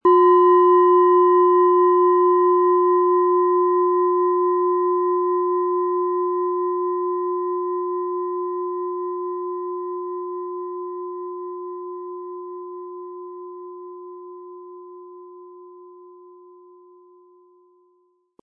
• Mittlerer Ton: Mond
• Höchster Ton: Mond
Unter dem Artikel-Bild finden Sie den Original-Klang dieser Schale im Audio-Player - Jetzt reinhören.
Im Lieferumfang enthalten ist ein Schlegel, der die Schale wohlklingend und harmonisch zum Klingen und Schwingen bringt.
PlanetentöneWasser & Mond
MaterialBronze